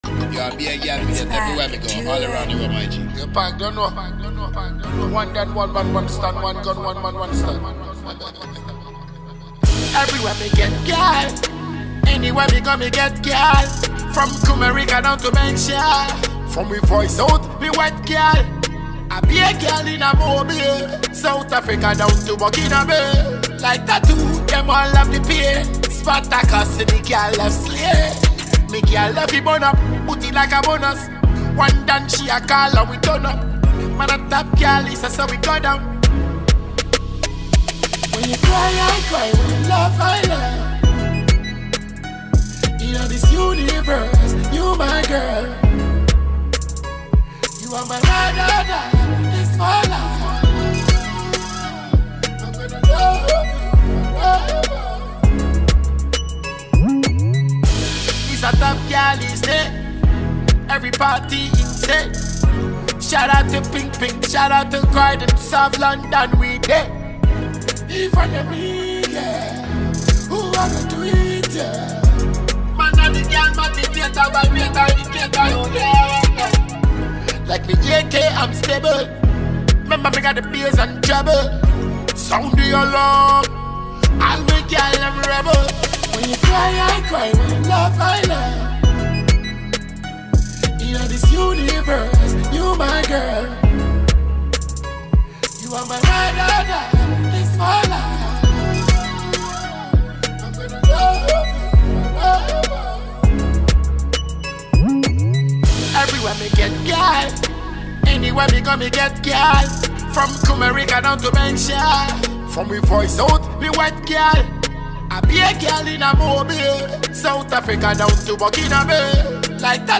Ghana Music
Ghanaian dancehall